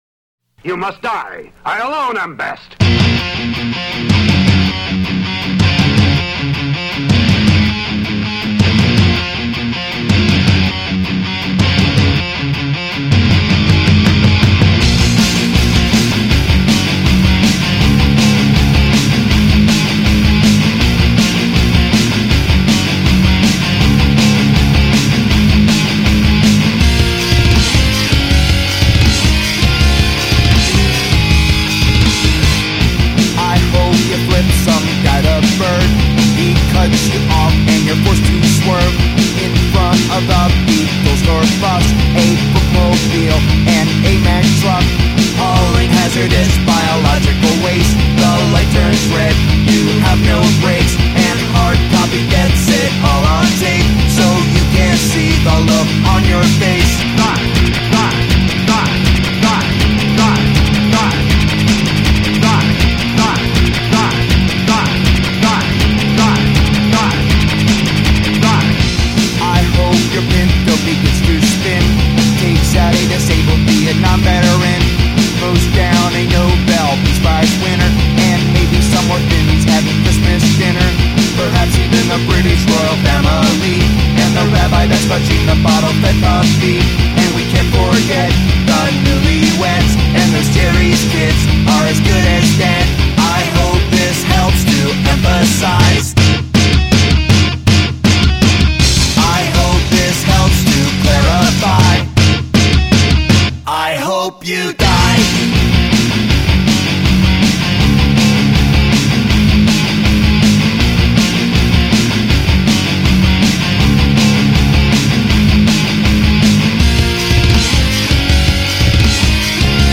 Tags: Metal Drum n bass hip hop